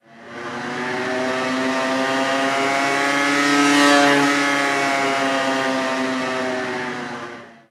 Moto Vespino pasando
moto
motocicleta
Sonidos: Transportes